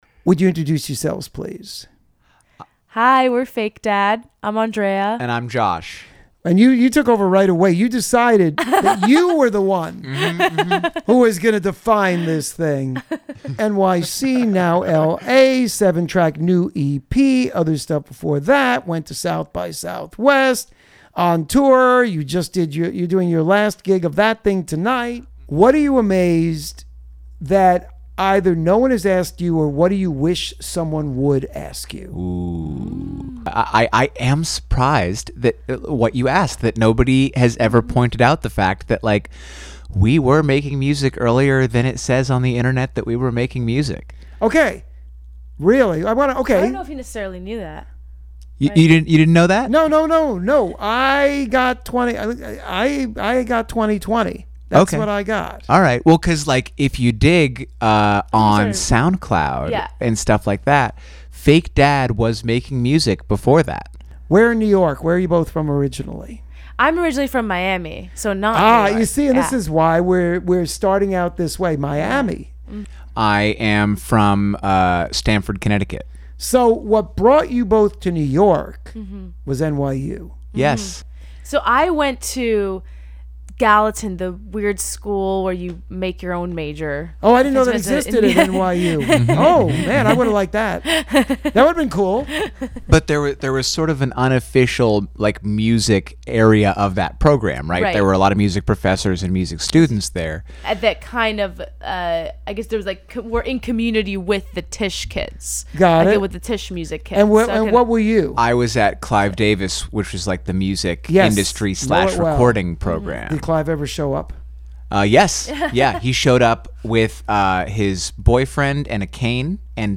This Week's Interview (04/06/2025): Fake Dad LISTEN TO THE INTERVIEW